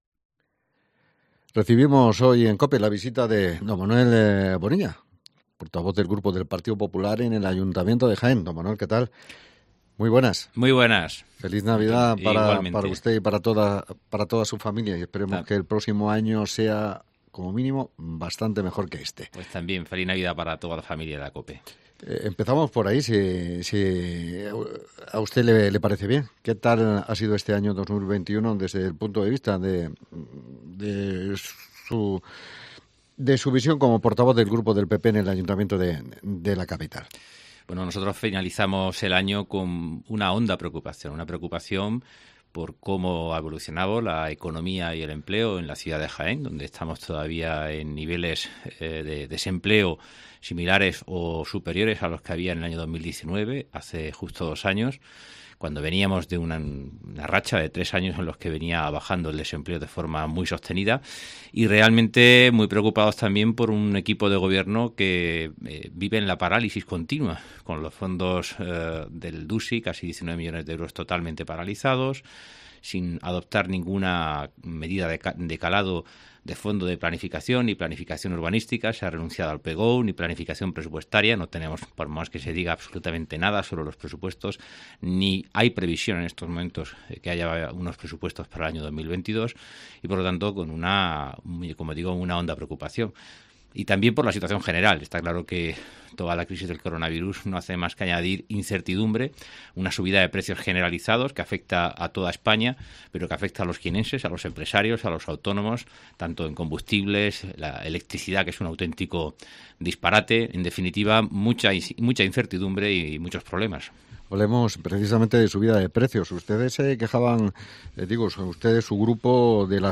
Hoy en COPE hemos charlado con el portavoz del grupo del PP en el Ayuntamiento de Jaén sobre diferentes temas y asuntos de actualidad local. Bonilla ha criticado la inacción del Equipo de Gobierno que sigue dejando escapar pobilidades de desarrollo y dinamización de la ciudad